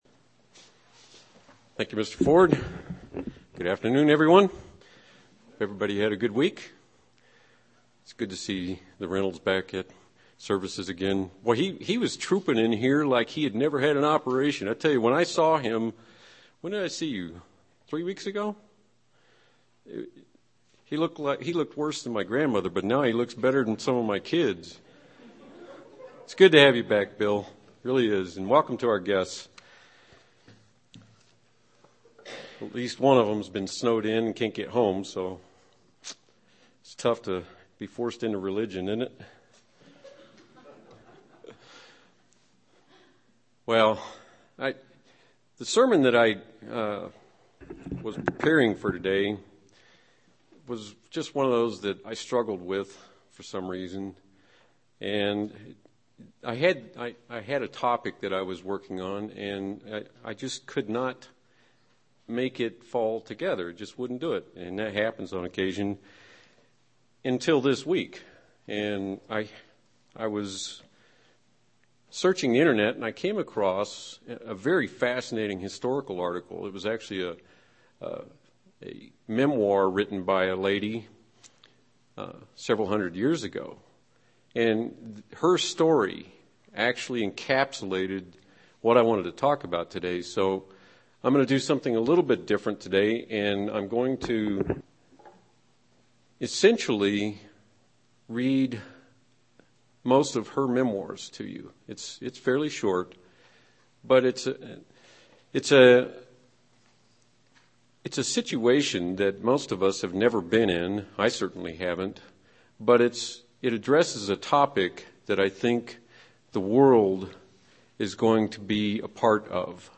UCG Sermon Studying the bible?
Given in Albuquerque, NM